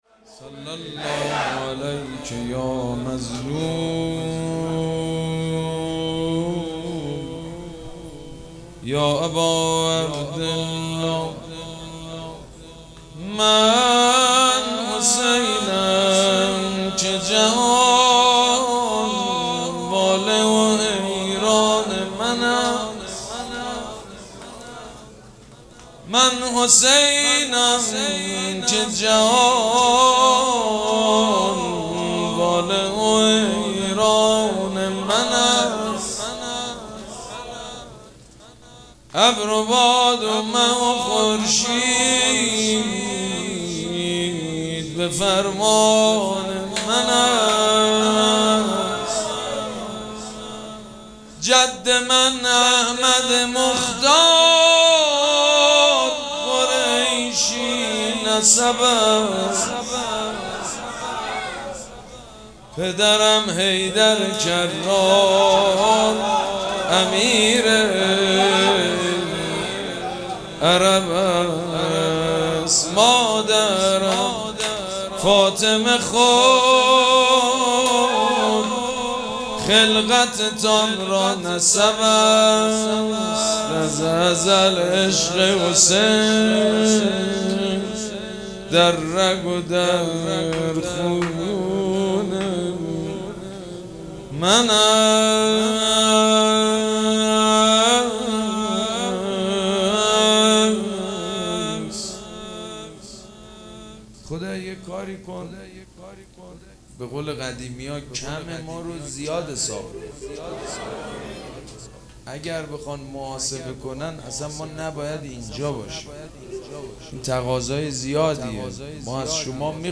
مراسم عزاداری شام شهادت حضرت رقیه سلام الله علیها
شعر خوانی
حاج سید مجید بنی فاطمه